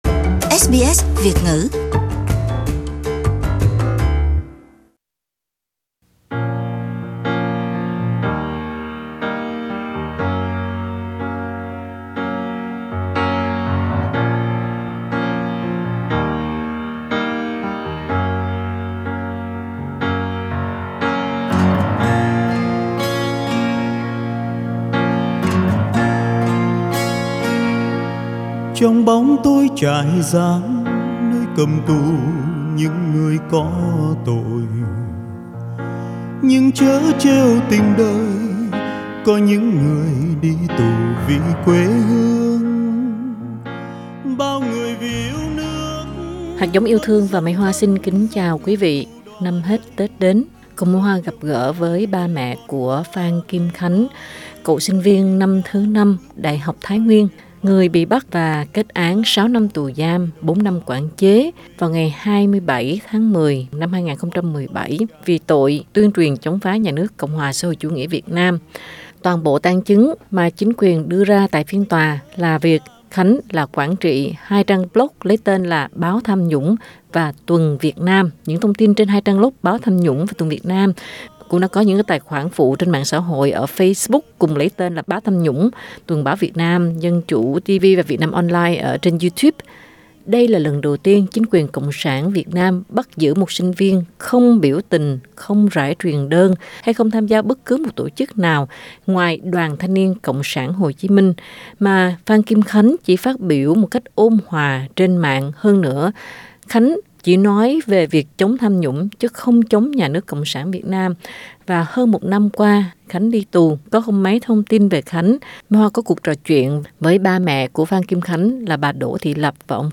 giấu tiếng khóc trong tiếng cười gãy vụn của mình vì kinh hoàng và thương con.
Trong cuộc trò chuyện